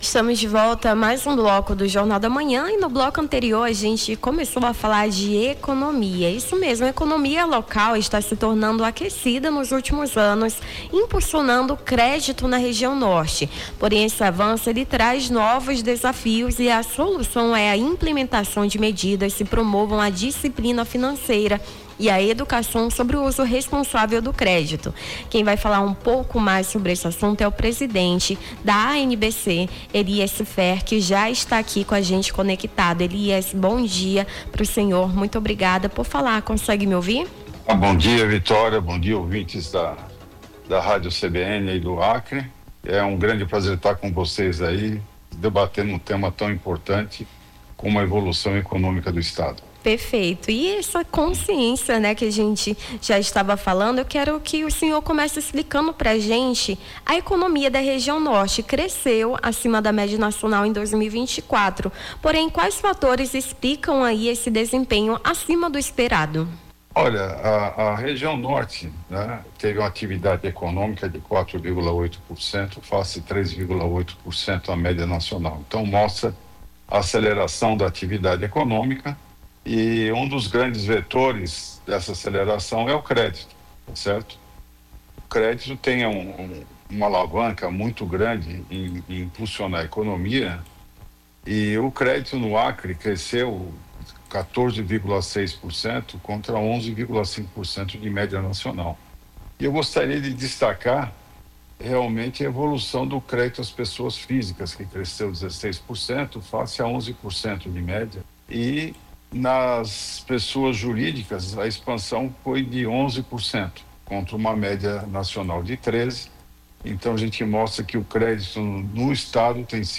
Baixar Esta Trilha Nome do Artista - CENSURA- (EMTREVISTA CRÉDITO NA REGIÃO NORTE) 07-04-25.mp3 Foto: internet/ Freepik Facebook Twitter LinkedIn Whatsapp Whatsapp Tópicos Rio Branco Acre Uso responsável Crédito Educação financeira